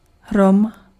Ääntäminen
Ääntäminen France: IPA: [fudʁ] Haettu sana löytyi näillä lähdekielillä: ranska Käännös Konteksti Ääninäyte Substantiivit 1. blesk {m} 2. hrom {m} meteorologia Suku: f .